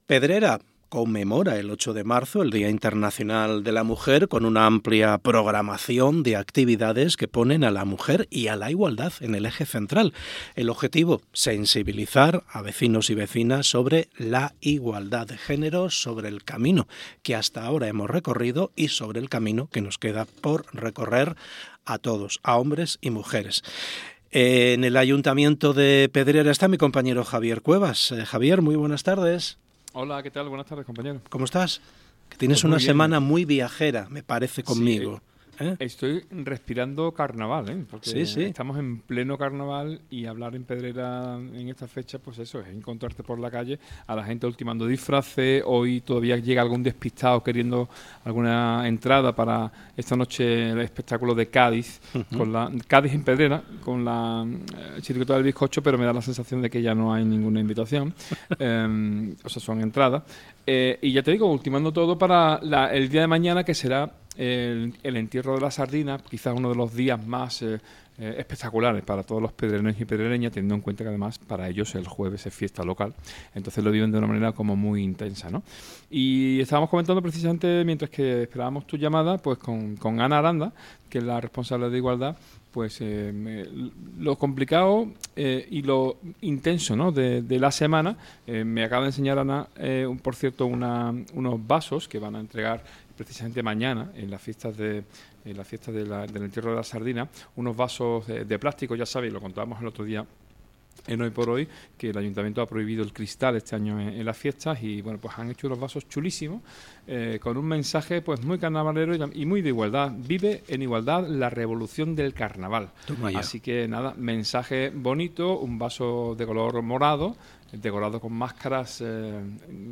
PEDRERA 8M Entrevista Ana Aranda - Andalucía Centro
El objetivo, sensibilizar a los vecinos y vecinas de Pedrera sobre la Igualdad de Género. Ana Aranda, concejala delegada de Igualdad, Mujer y Bienestar Social, ha pasado por el programa HoyxHoy SER Andalucía Centro.